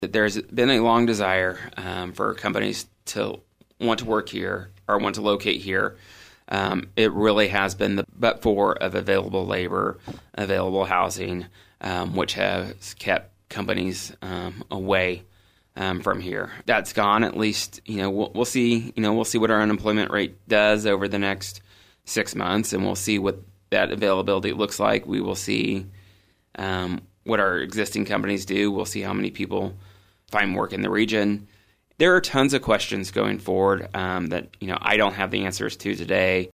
Q&A With Trey is a recurring segment with Emporia City Manager Trey Cocking on KVOE's Talk of Emporia every third Monday.